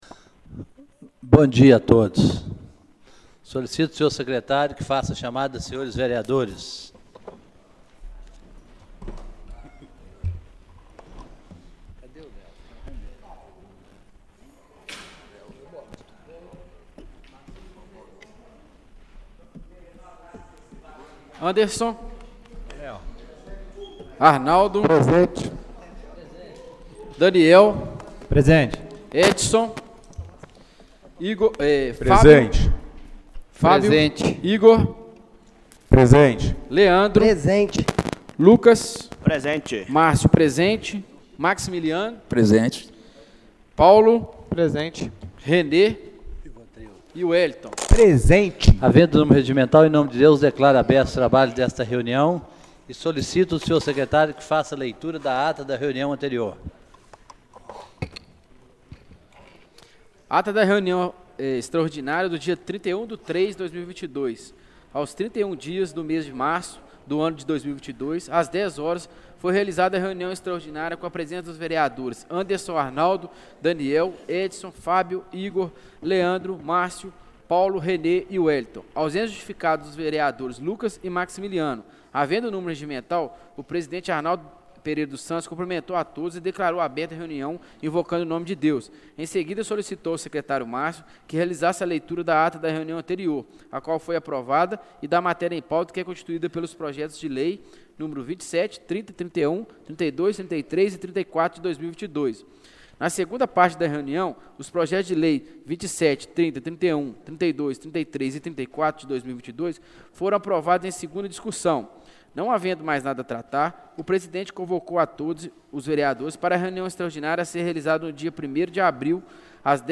Reunião Extraordinária do dia 01/04/2022